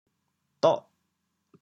“着”字用潮州话怎么说？
☞此义项潮州话白读为[doh8]。
doh8.mp3